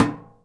metal_tin_impacts_soft_07.wav